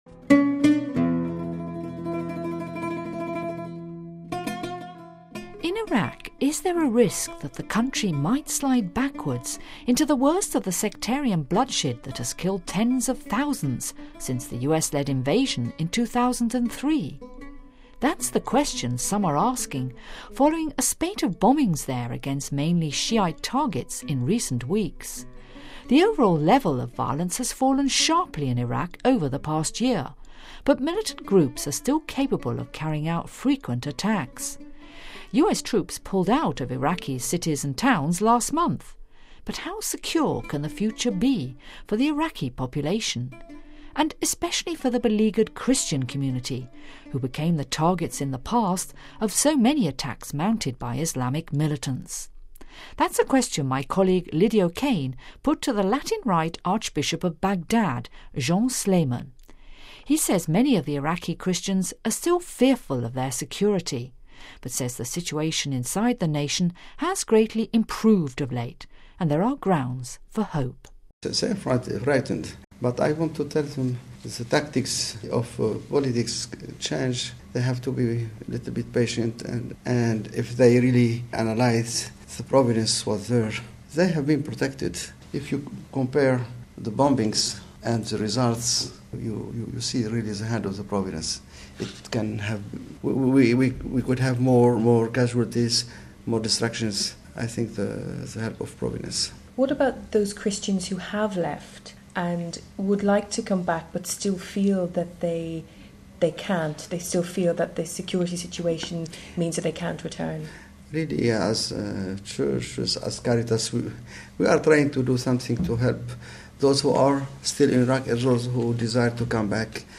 Home Archivio 2009-07-22 16:29:03 IRAQIS DARE TO HOPE The Latin-rite Archbishop of Baghdad, Jean Sleiman, talks to us about his hopes for the future amid the continuing problems facing the country....